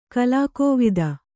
♪ kalākōvida